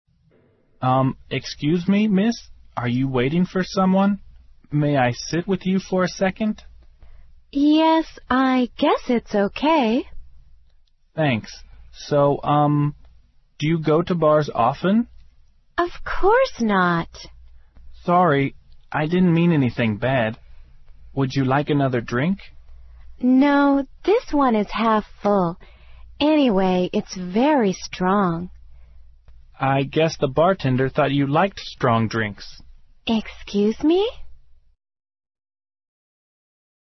网络社交口语对话第19集：你在等人吗？